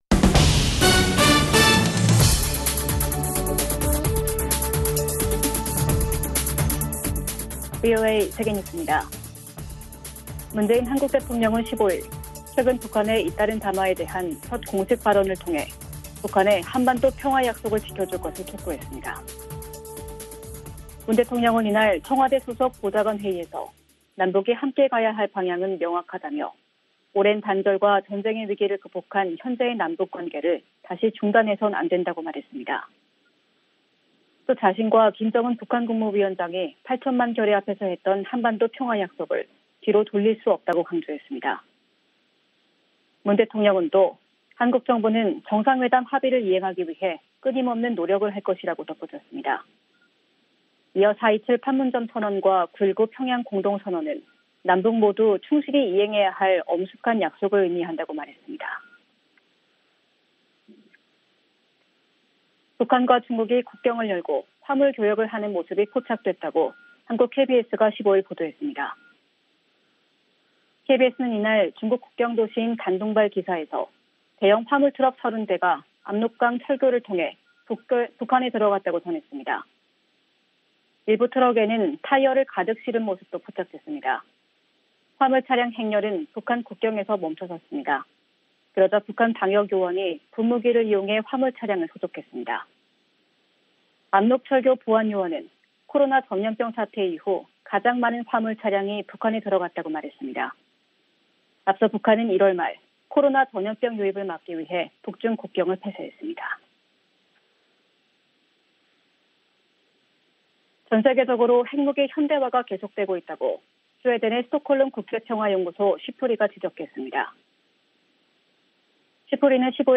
VOA 한국어 아침 뉴스 프로그램 '워싱턴 뉴스 광장' 2020년 6월 16일 방송입니다. 문재인 한국 대통령은 북한이 최근 군사 도발을 시사하며 남북관계 긴장감을 고조시킨 데 대해 한반도 평화의 약속을 뒤로 돌릴 수는 없다고 말했습니다. 미국 의회가 주한미군 감축에 관한 행정부의 독자적 결정을 제한할 수 있게 하는 조항이 상원의 새 국방수권법안에 포함됐습니다.